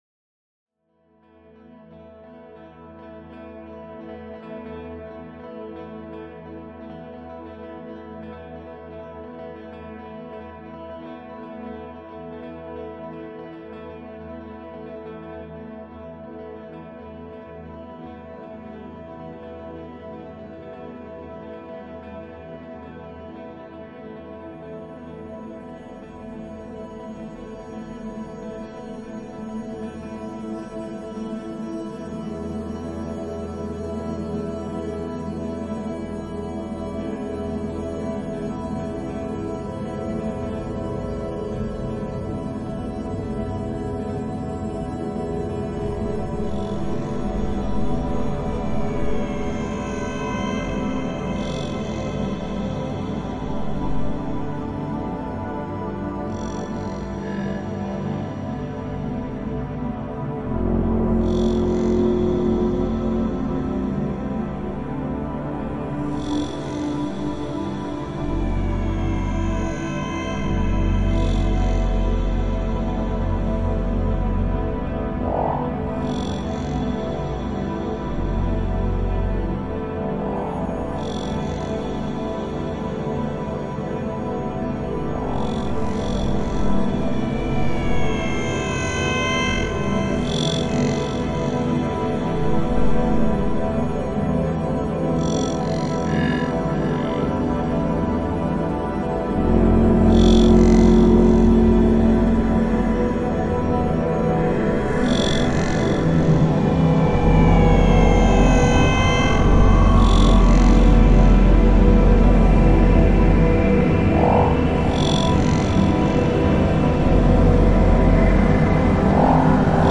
Channels: 2 | Samplerate: 48000 | Genre: Electronic | Instrument: Multiple
Generativa
drones-generativos.mp3